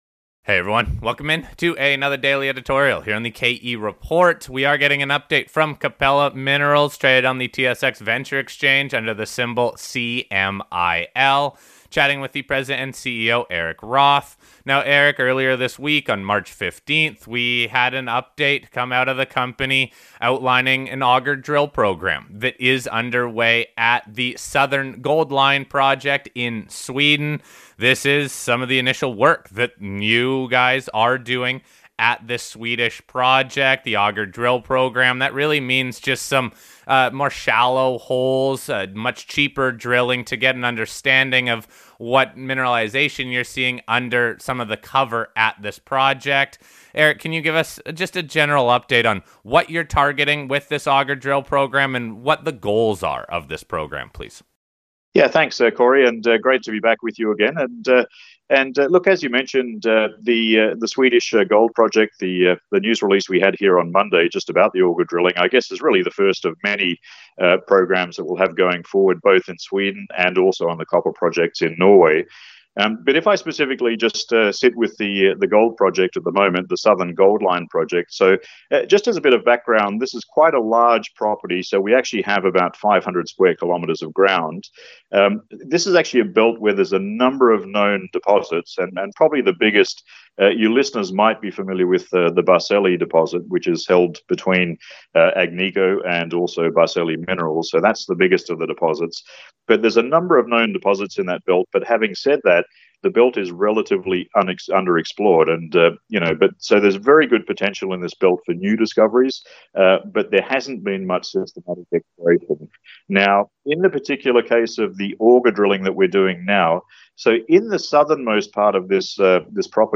We discuss the goals of the program and the mineralized Belt that is being tested with the auger drilling. We also get an update on the progress of the Norway Projects toward drilling.